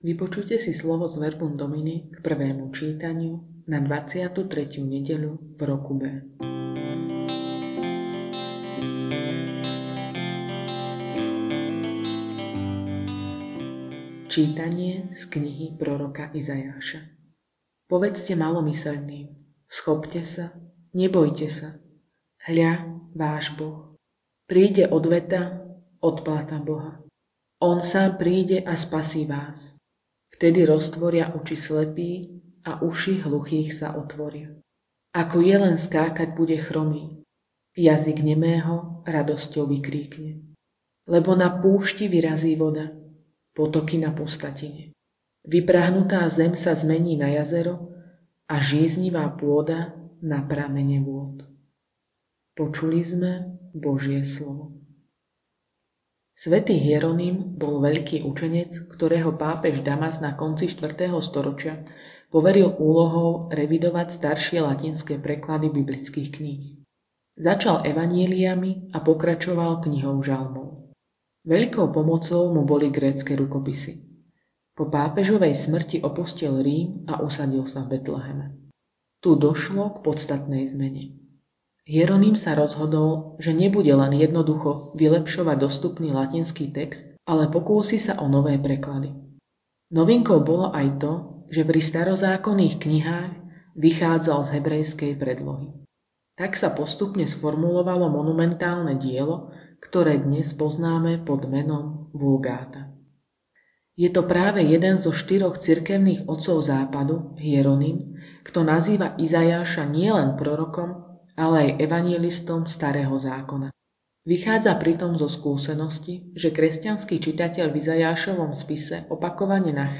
Audionahrávka zamyslenia…